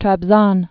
(trăb-zŏn, träb-zôn) or Treb·i·zond (trĕbĭ-zŏnd)